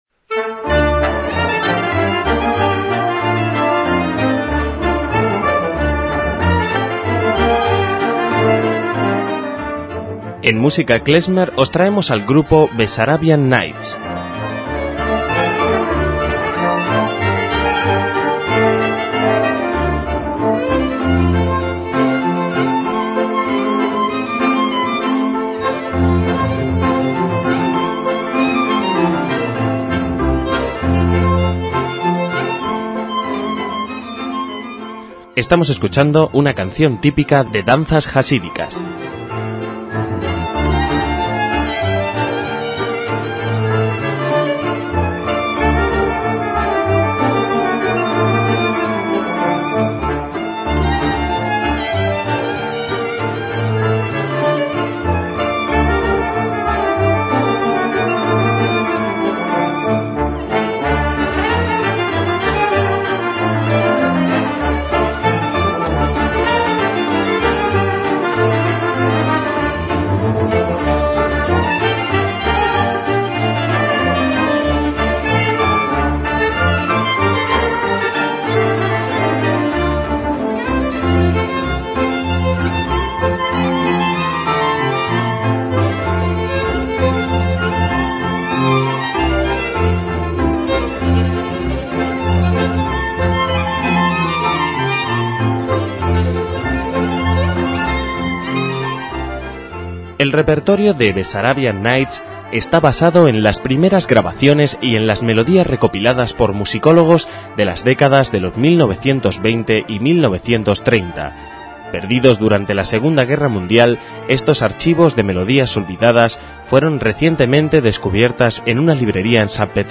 MÚSICA KLEZMER
violín
clarinete
trompeta
acordeón
contrabajo